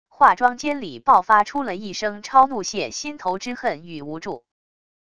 化妆间里爆发出了一声超怒泄心头之恨与无助wav音频